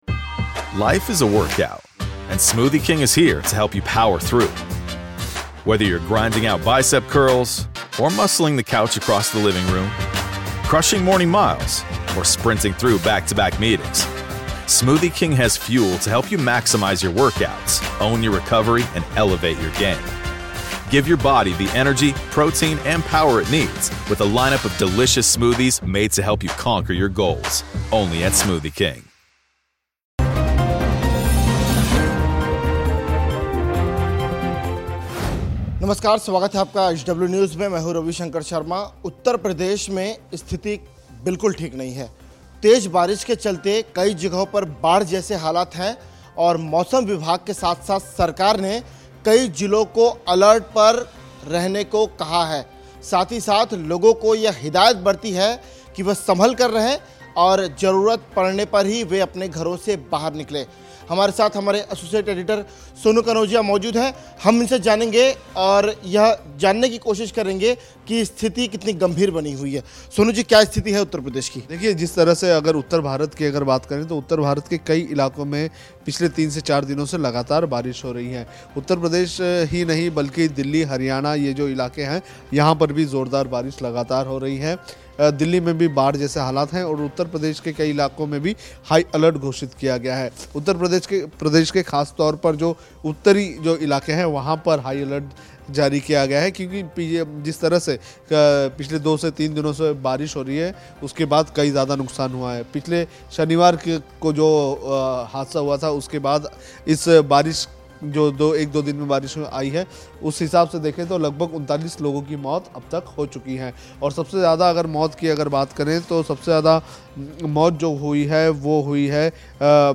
न्यूज़ रिपोर्ट - News Report Hindi / यूपी में मौत का तूफान, बारिश से अब तक 75 की मौत, खतरा बरकरार